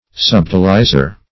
Subtilizer \Sub"til*i`zer\, n. One who subtilizes.